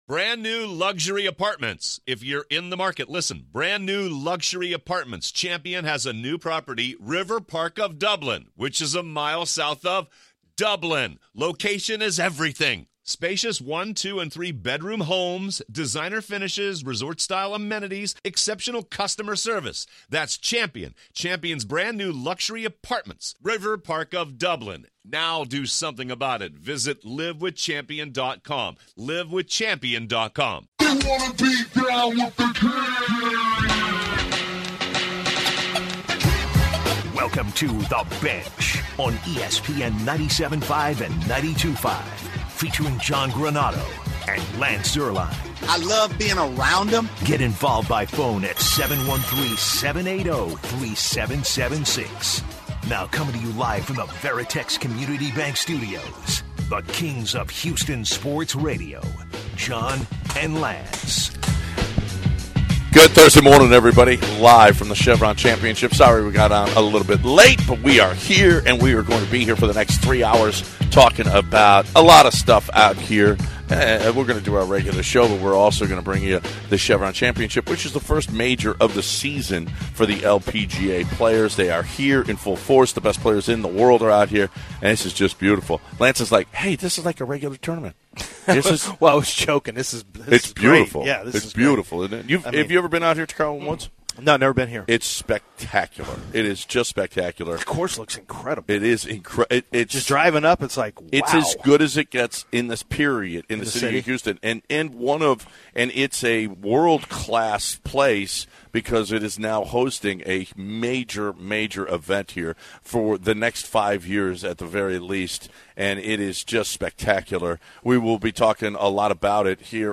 LIVE from The Club at Carlton Woods!